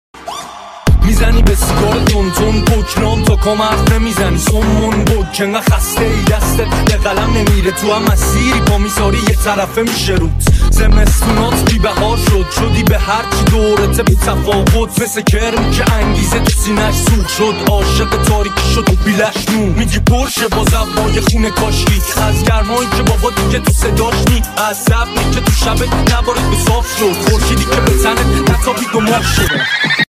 غمگین بیس دار تند